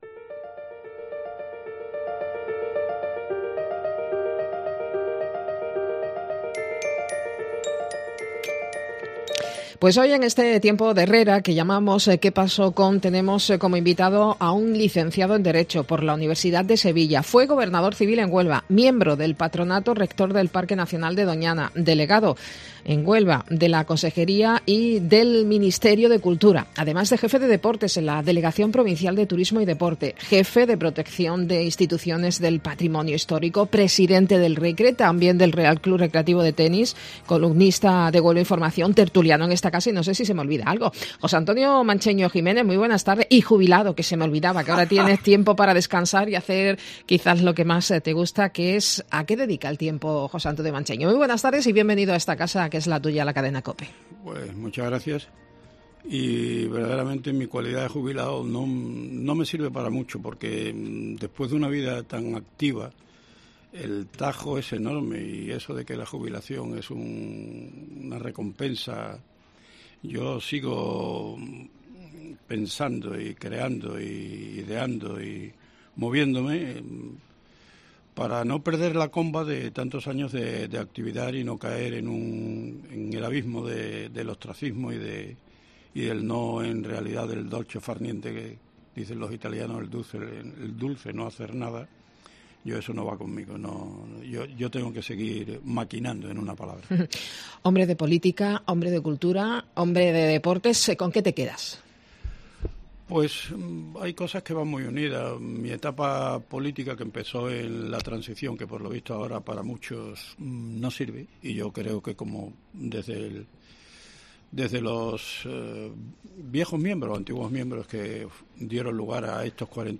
La política, la cultura y el deporte siempre han estado ligado a José Antonio Mancheño ( Tony para los amigos) un licenciado en Derecho por la Universidad de Sevilla, que fue gobernador en su tierra, Subdelegado del Gobierno en Córdoba, miembro del Patronato Rector del Parque Nacional de Doñana, delegado en Huelva de la Consejería y del Ministerio de Cultura, jefe de Deportes en la Delegación Provincial de Turismo y Deporte, también jefe de Protección de Instituciones del Patrimonio Histórico, además de Presidente del Recre y del Real Club Recreativo de Tenis y Secretario de UCD, toda una institución en Huelva que habla sin tapujos del cambio político en la comunidad andaluza, tras 37 de gobierno socialista, del Recre y de las elecciones municipales del próximo mes de Mayo donde puede haber cambio en la alcaldía porque hay partido, deciá Mancheño, el invitado en el espacio Que pasó con de Herrera en Cope